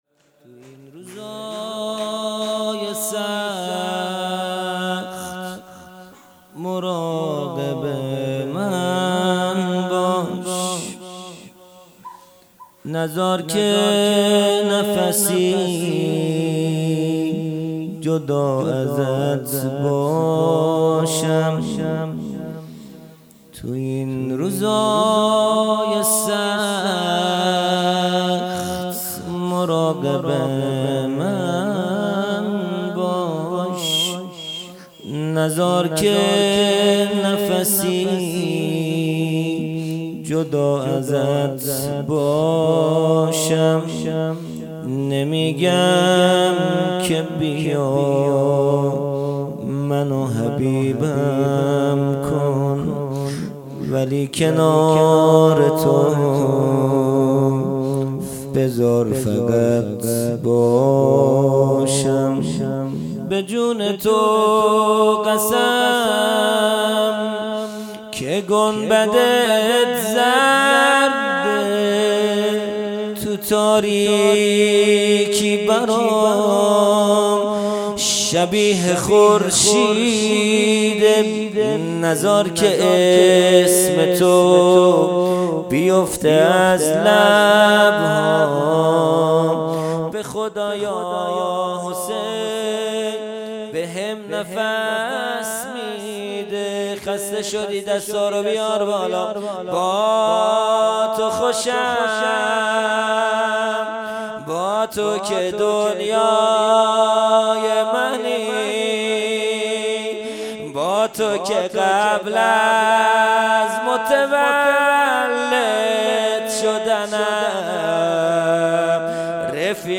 جلسۀ هفتگی | مناجات ماه رمضان | پنج شنبه 25 فروردین 1401